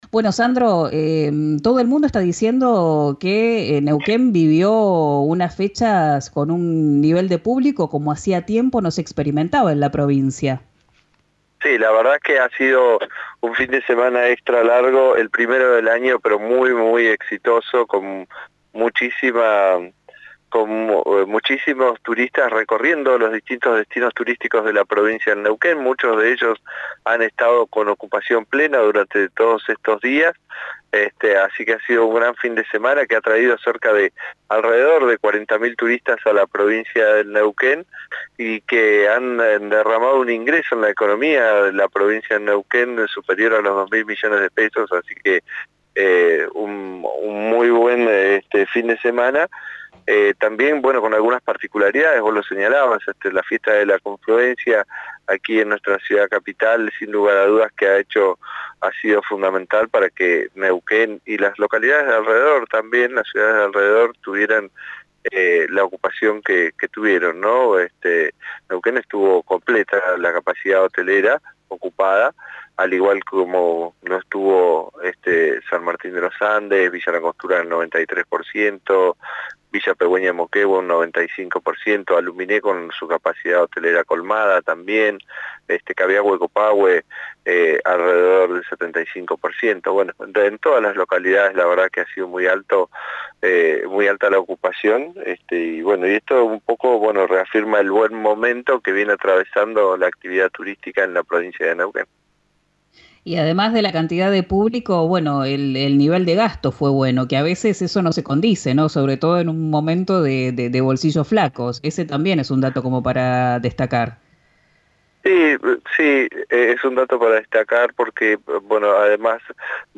Escuchá al ministro de Turismo, Sandro Badilla en «Quien dijo verano» por RÍO NEGRO RADIO: